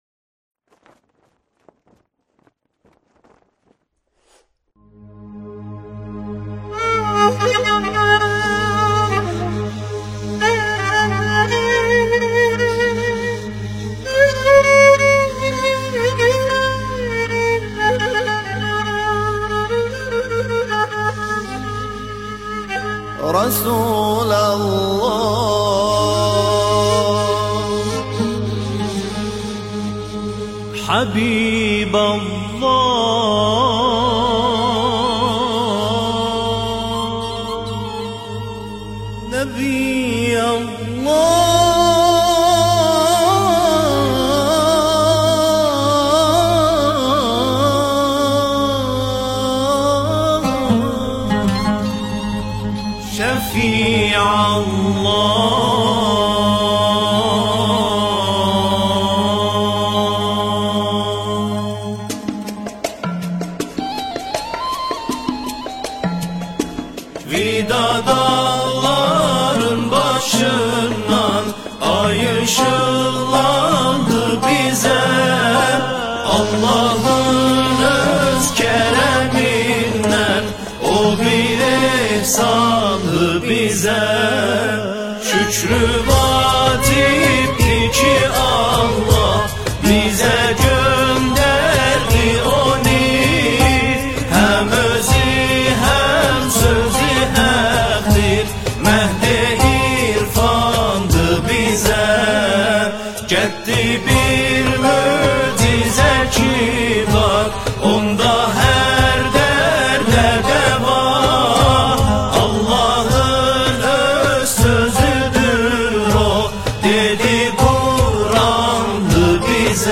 نماهنگ زیبای آذری